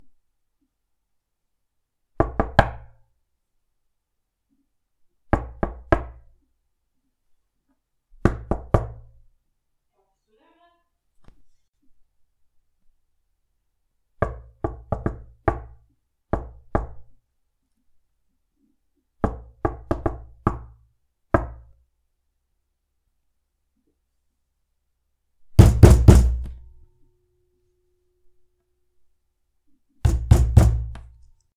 敲门声
描述：录制我敲我的桌子。节奏变化。
Tag: 书桌 节奏 踩住 敲击 旋律